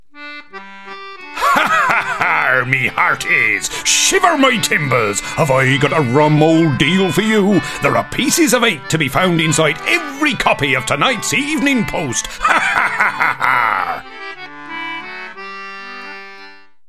Character and Cartoon voices
Pirate character – lots of ha argh